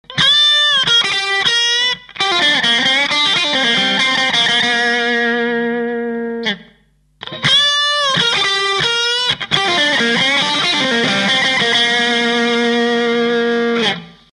今回は高域の感じを優先し